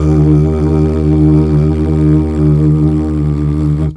Flute 51-03.wav